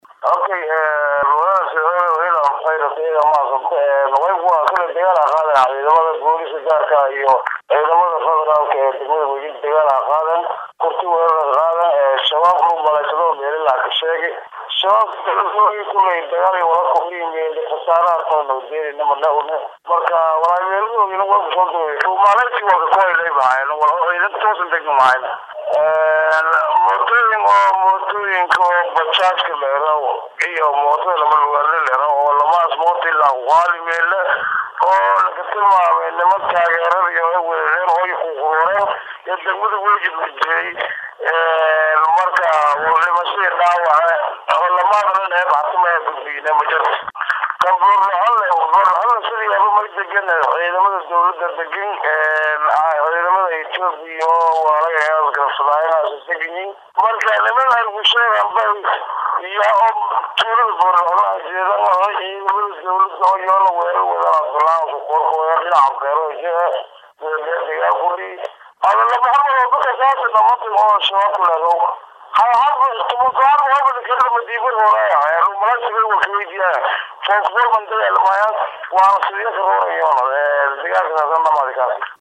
Gudoomiye Ku xigeenka Degmada Waajid Ee Gobolka Bakool Cadow Cabdiraxmaan Ayaa Waxaa Uu Ka warbixiyey Werarkaas Iyo Khasaraha uu Geestey.